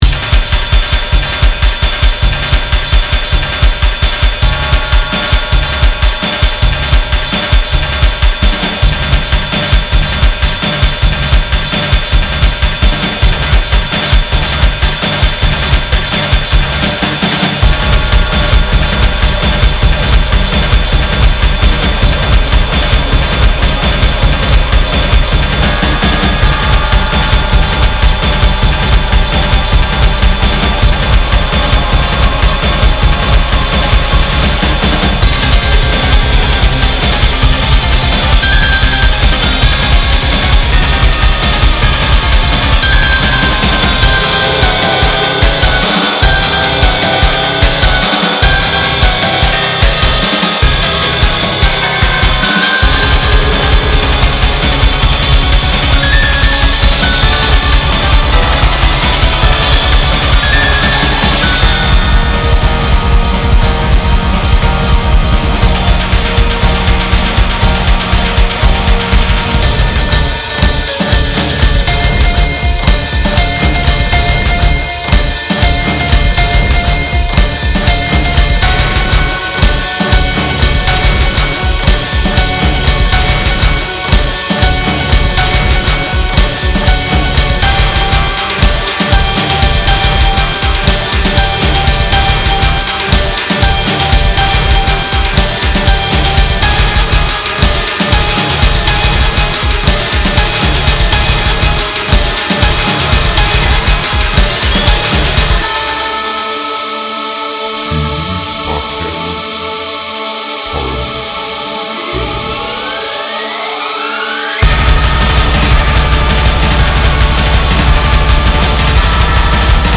BPM240-400
Audio QualityPerfect (High Quality)
Remix
I do not use 4/4 a lot.
-One vocal sample can be heard.